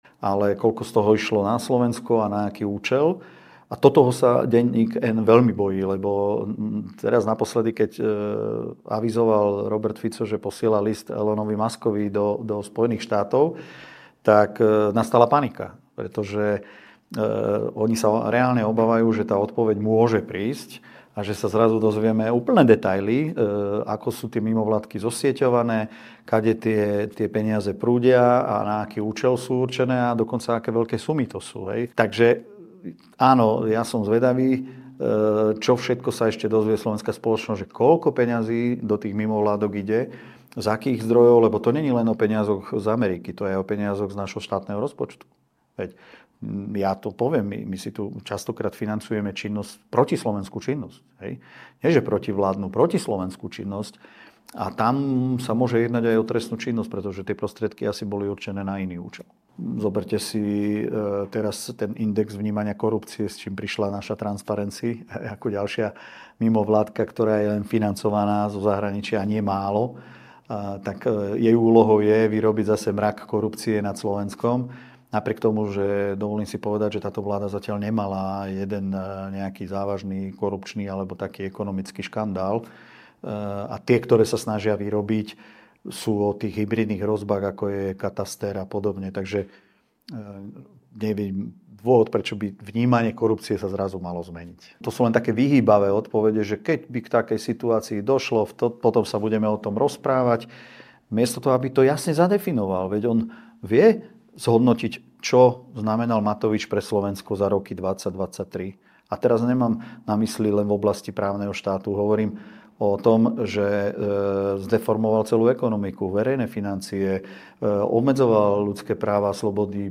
NAŽIVO